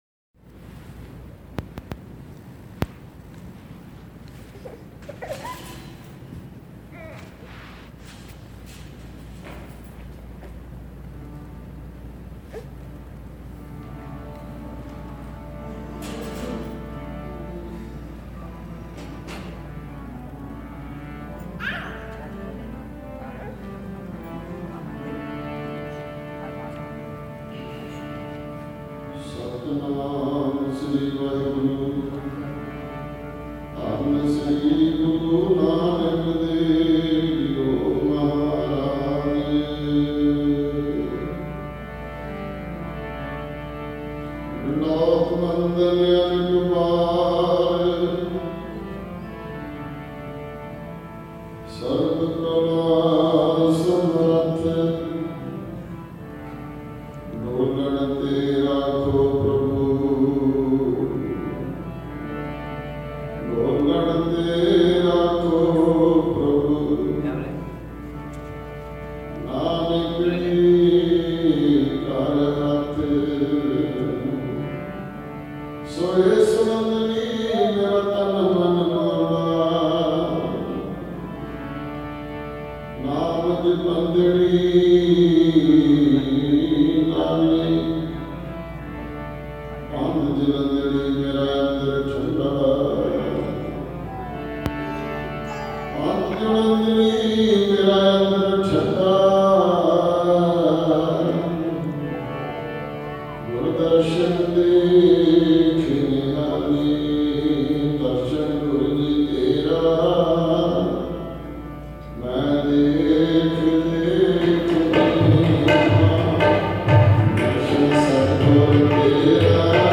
Roohani Kirtan – Nanaksar Gurdwara, Toronto – Day 4